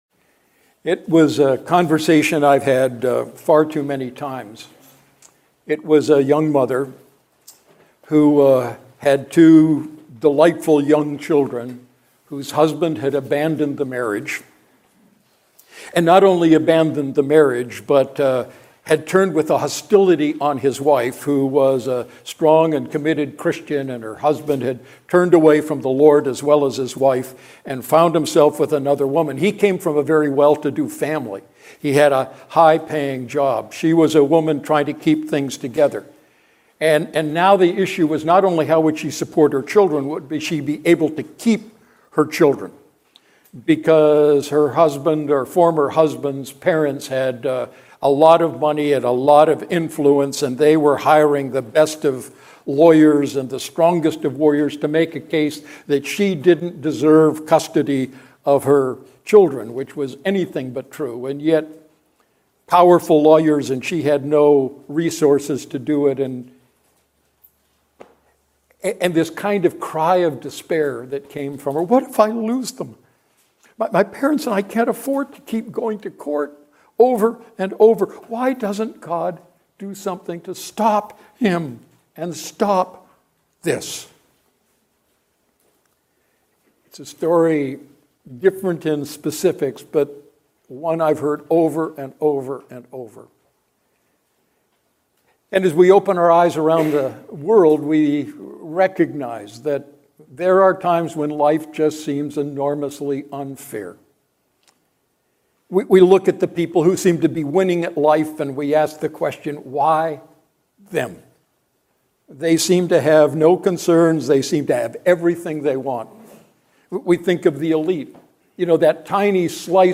Standalone Sermon